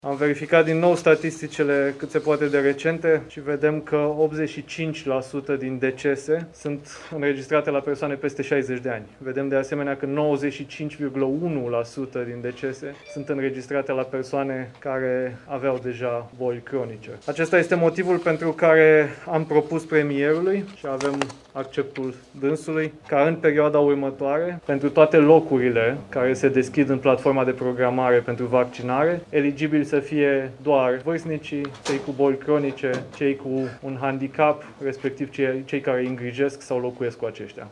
Pe perioada lunii februarie toate locurile oferite în platforma de vaccinare vor merge către aceștia, nu către alte persoane”, a anunțat, sâmbătă, într-o conferință de presă, ministrul Sănătății, Vlad Voiculescu.